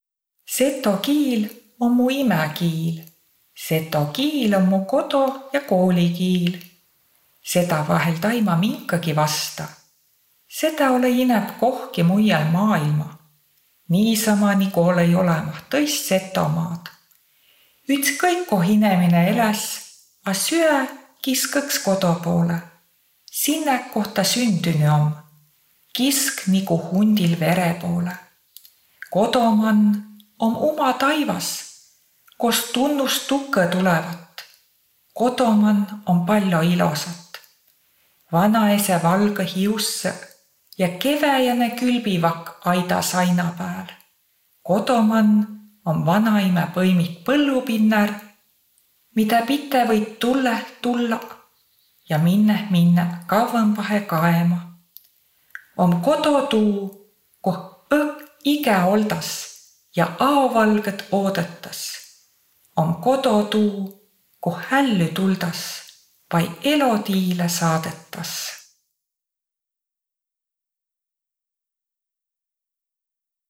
Saa pruuki ütehkuuh “Seto aabidsaga” (2011) N-tähe opmisõl vai eräle. Kukka Manni luulõtus “Seto kiil´”.